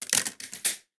token.ogg